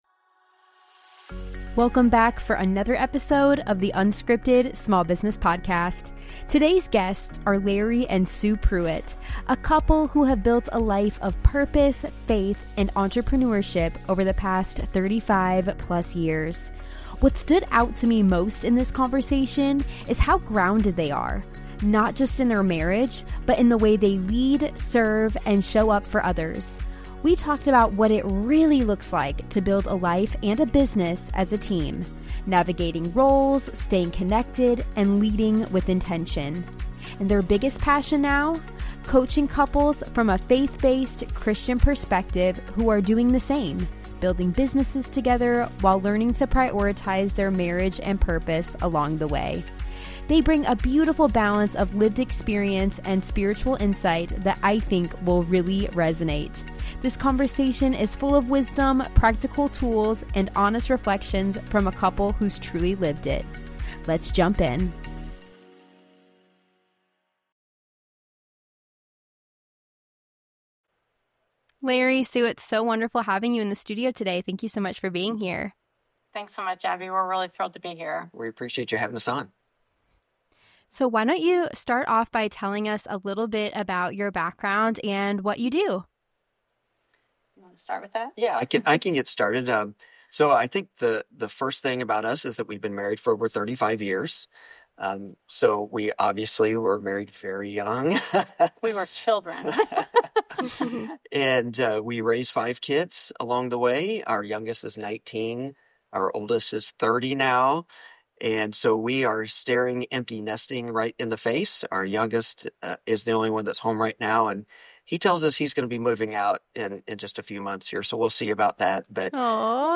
unscripted interviews small business owners, founders and creators across the United States, learning about their challenges, successes and insights into the world of SMBs.